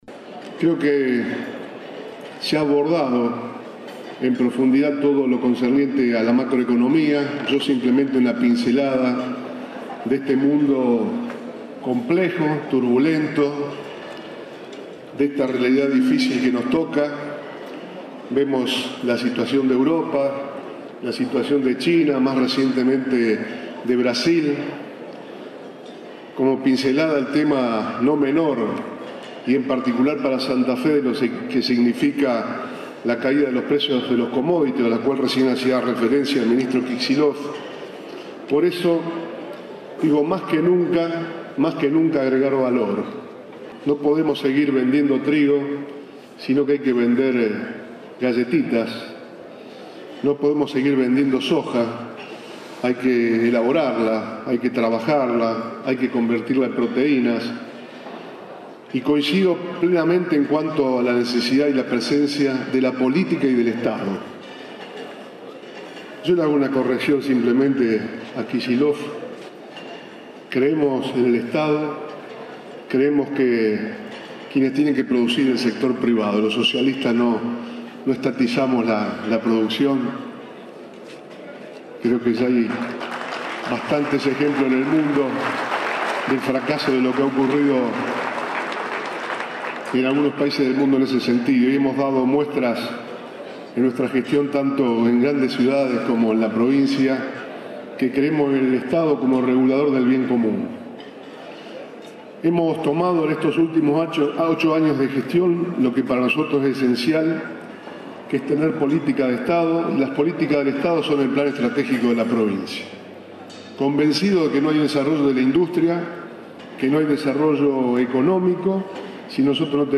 Bonfatti participó en Casilda de la celebración del Día de la Industria